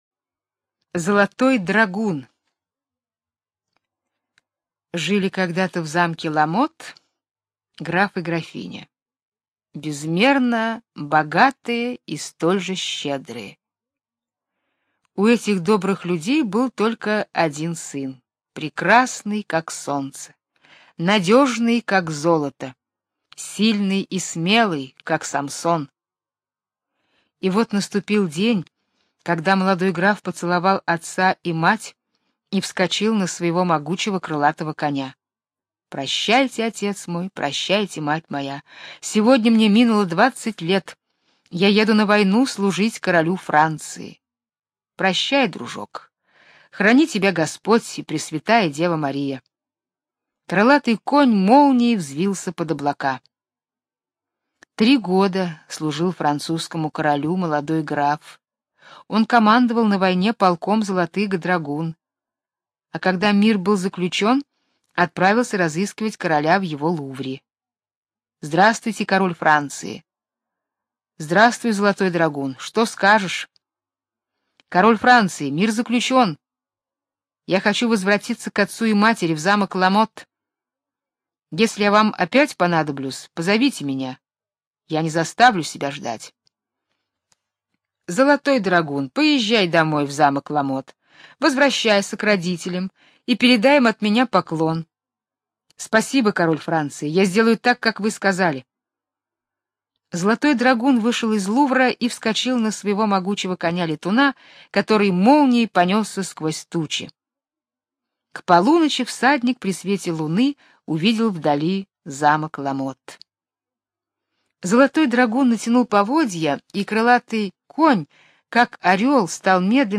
Золотой Драгун - французская аудиосказка - слушать онлайн